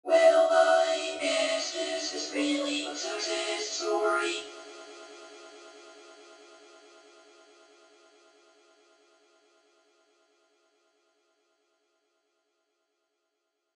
标签： 唱歌 声音 唱功 声音
声道立体声